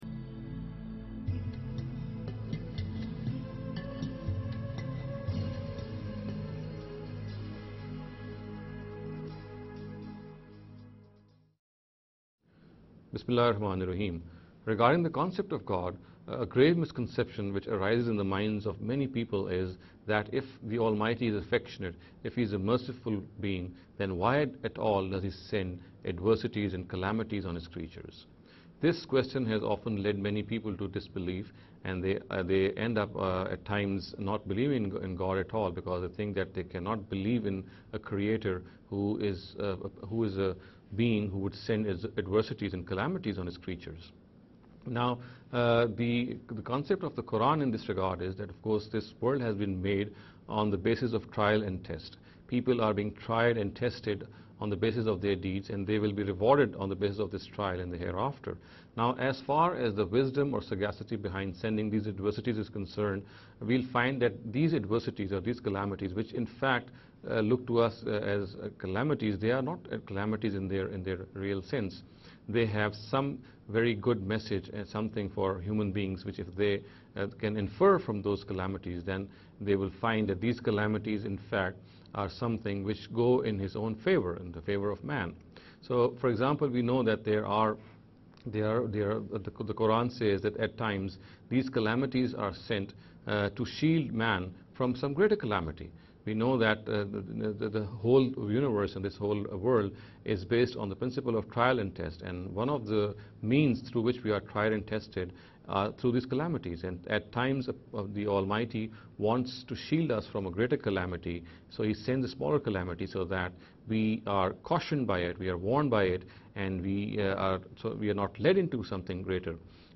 This lecture series will deal with some misconception regarding the concept of God.